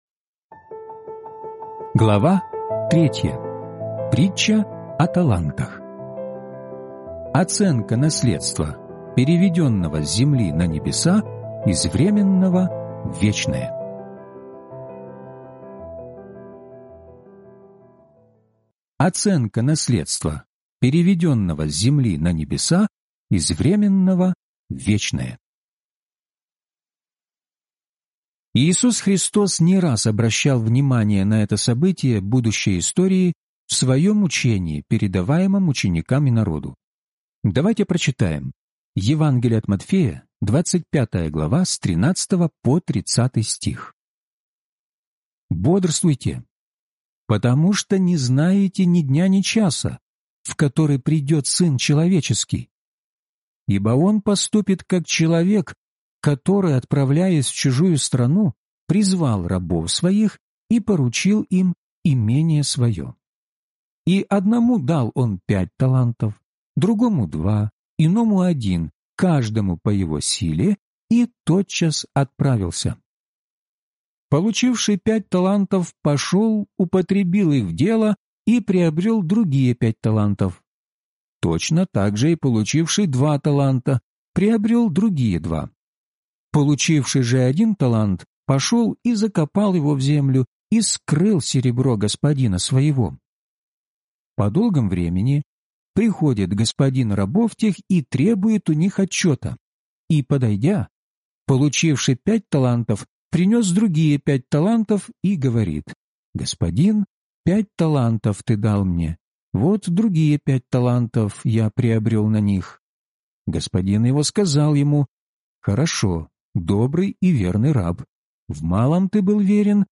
Судилище Христово (аудиокнига) - День 3 из 12